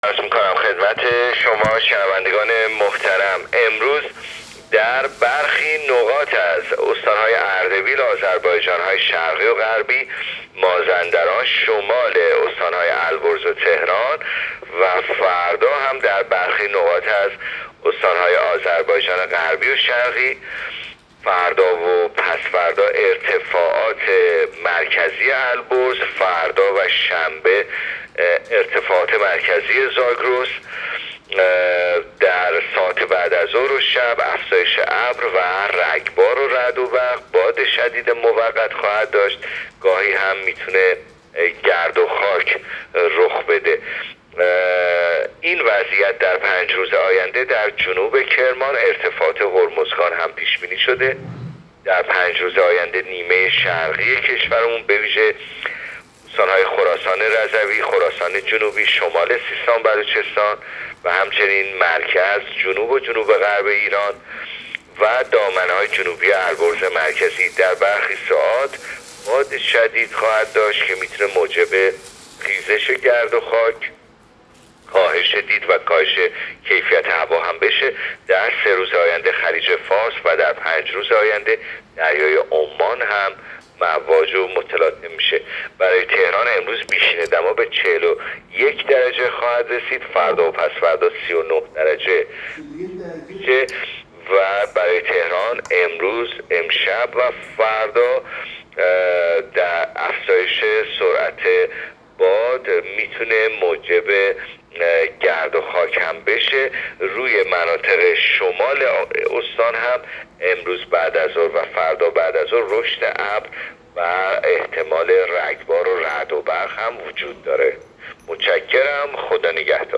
گزارش رادیو اینترنتی پایگاه‌ خبری از آخرین وضعیت آب‌وهوای ۸ مرداد؛